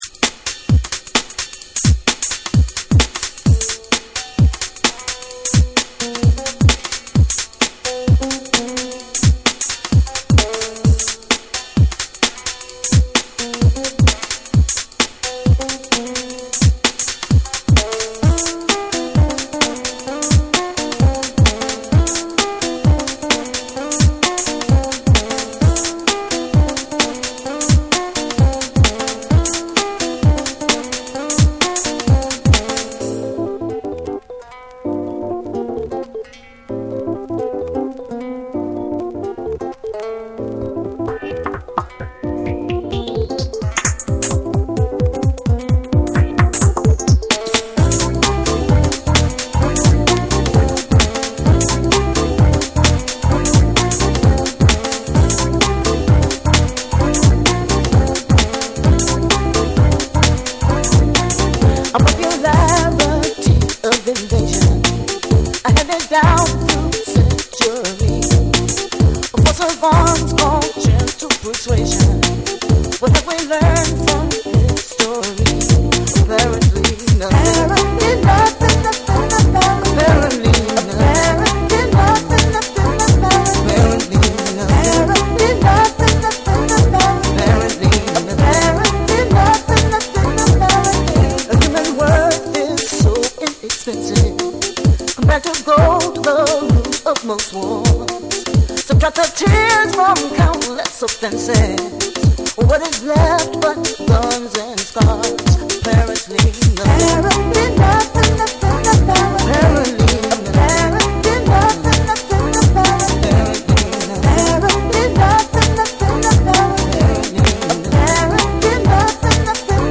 TECHNO & HOUSE / BREAKBEATS / SOUL & FUNK & JAZZ & etc